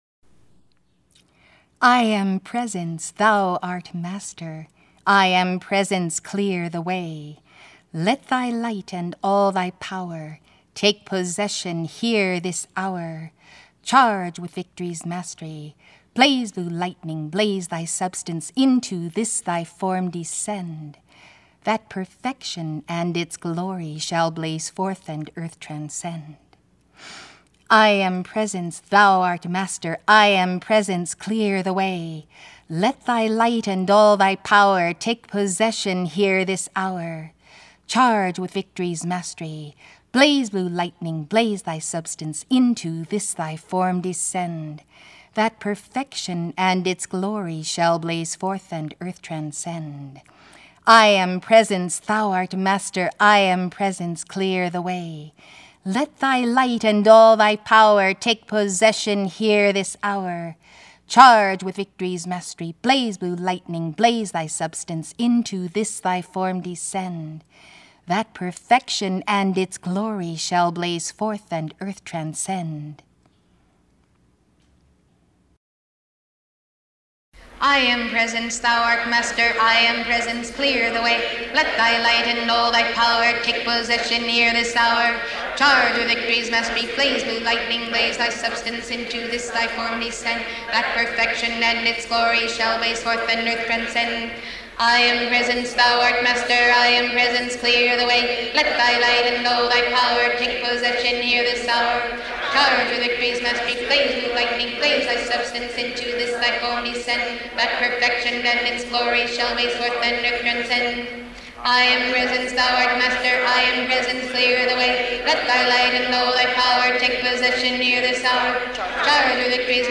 Audios de decretos de la página 15 en voz de la Mensajera
I AM Presence, Thou Art Master (3x lento y 3x normal)
Descargar audios de Ángeles de protección 1 en voz de la Mensajera y la congregación